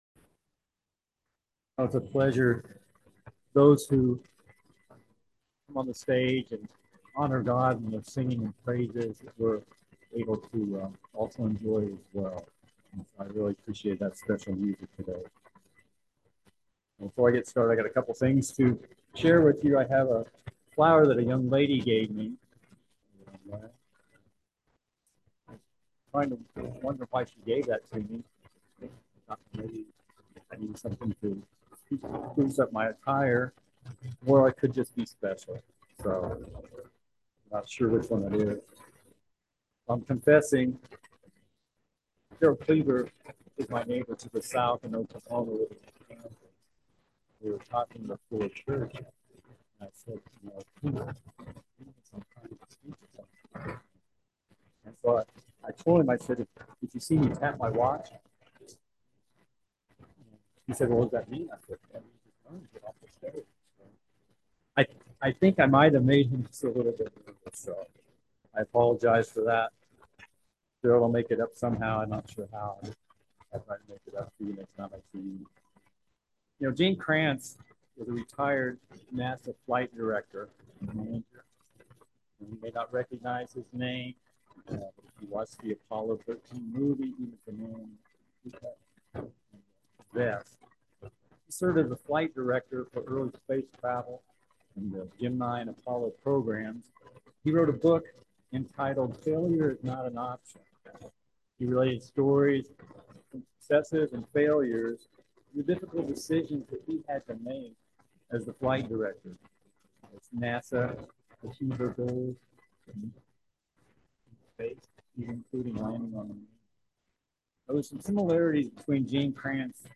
This sermon was given at the Lake Geneva, Wisconsin 2023 Feast site.